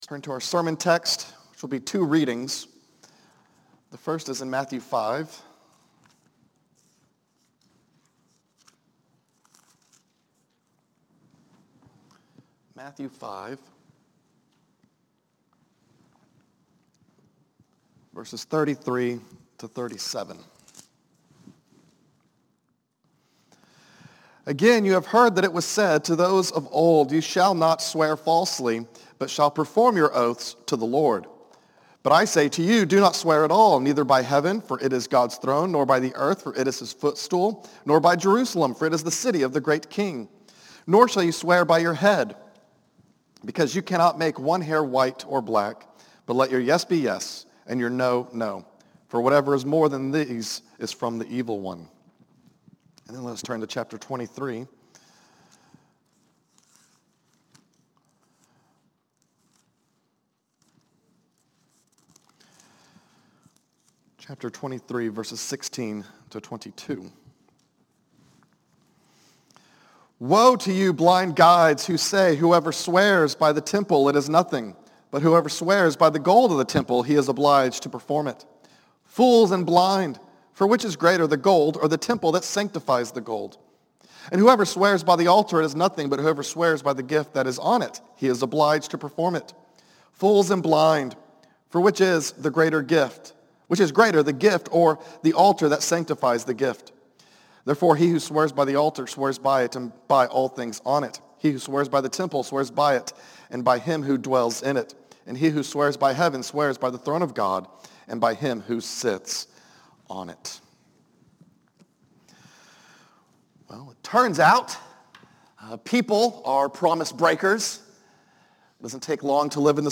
Afternoon Service